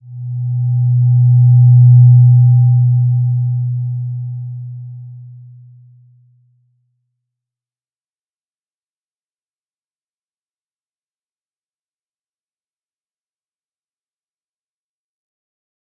Slow-Distant-Chime-B2-p.wav